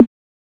Perc (Impossible)(1).wav